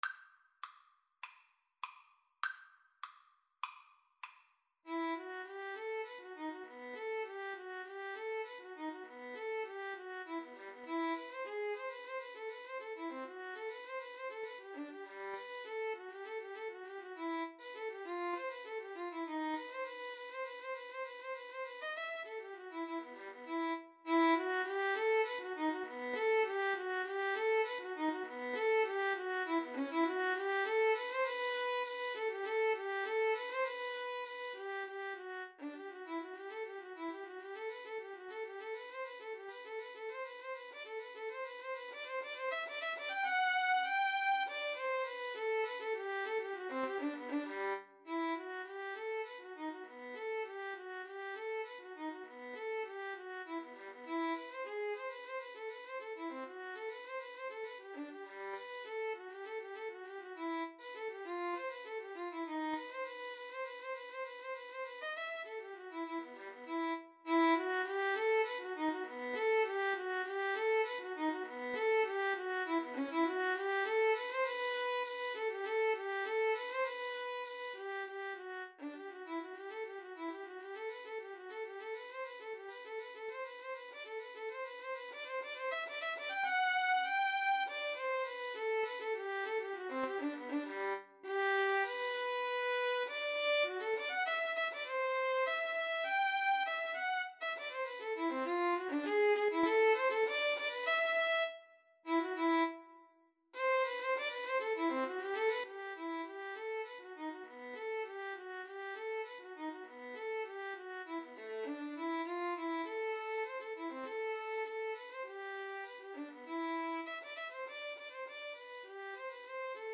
~ = 100 Allegro (View more music marked Allegro)
Classical (View more Classical Violin-Cello Duet Music)